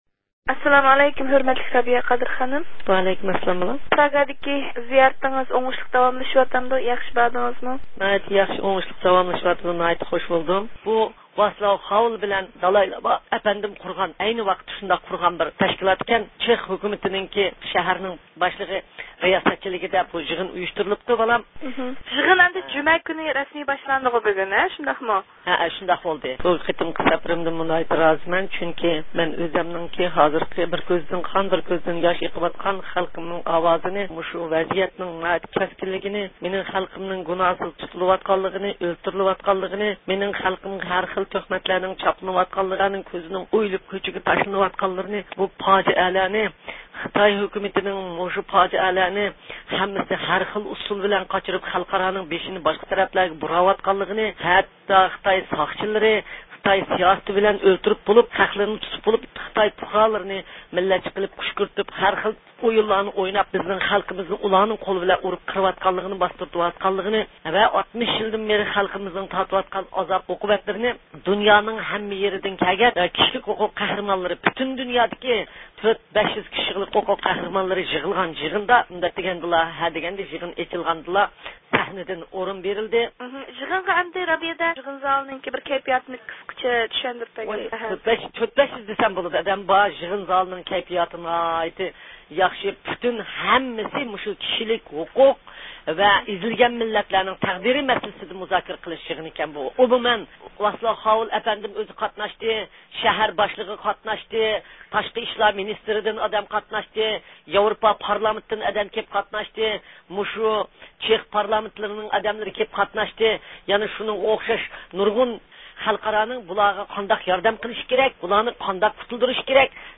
بۇ مۇناسىۋەت بىلەن پراگادا يىغىنغا قاتنىشىۋاتقان رابىيە قادىر خانىم بىلەن تېلېفون سۆھبىتى ئۆتكۈزدۇق.